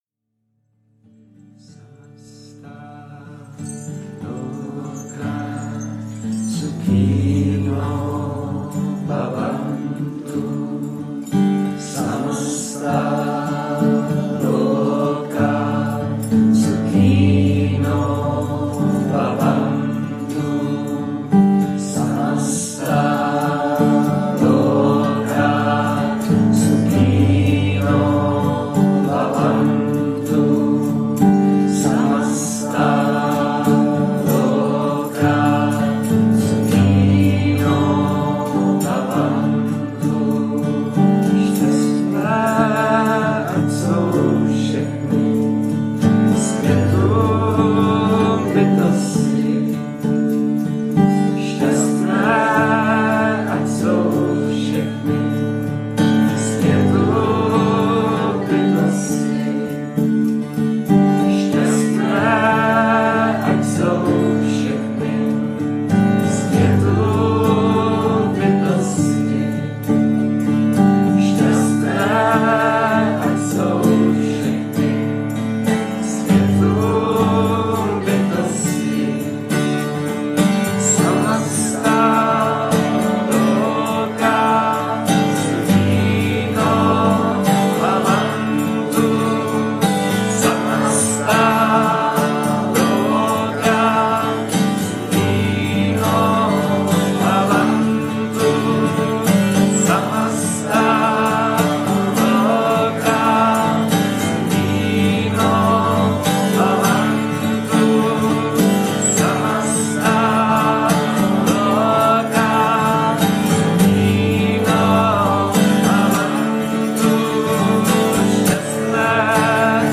Šánti mantra v sanskrtu s českým textem
nahrávka ze zpívání (live record).
1.verze - naše melodie vychází z tradiční recitované podoby k níž zpíváme i český překlad.